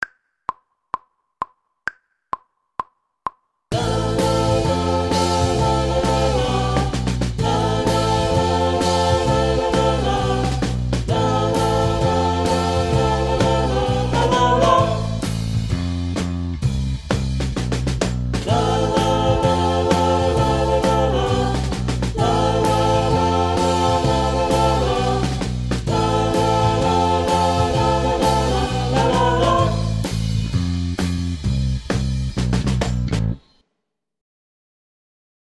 Passenger vocals section 8